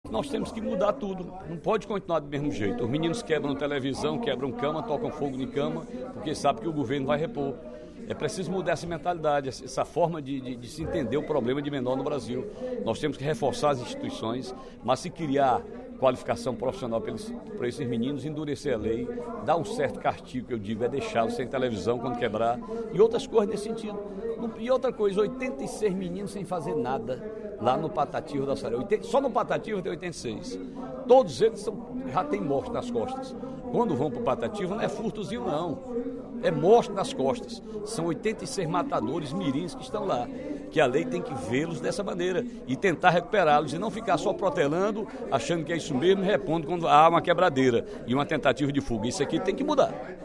O deputado Ferreira Aragão (PDT) apontou, durante o primeiro expediente da sessão plenária desta quinta-feira (23/03), as recorrentes rebeliões e fugas no Centro Educacional Patativa do Assaré, localizado em Fortaleza, no bairro Ancuri.